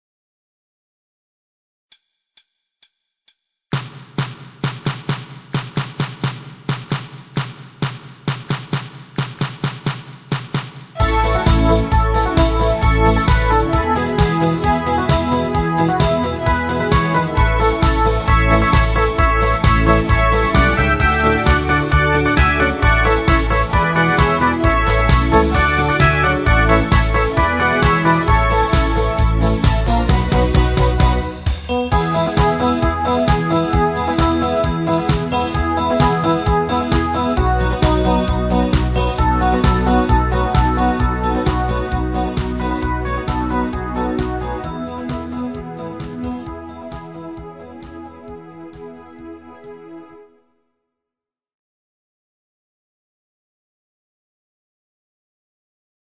Kategorie: Medley